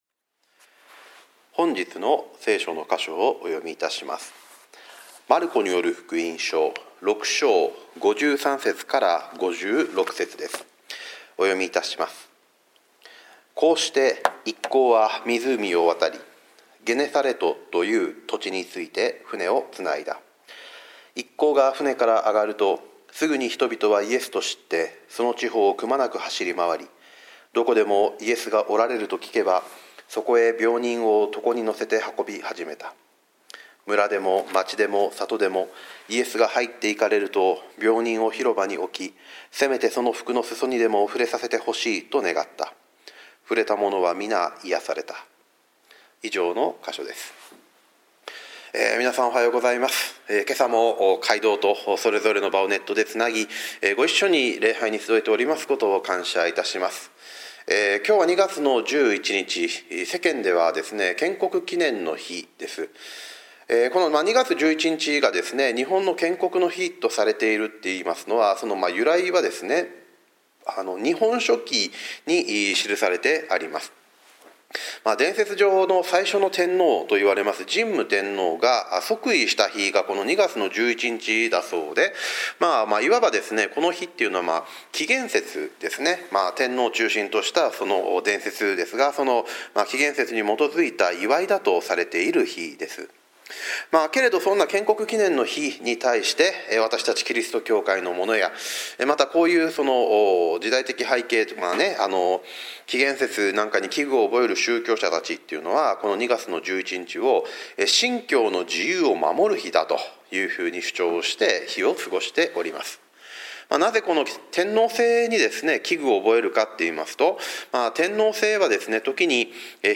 主日礼拝はおりしも建国記念の日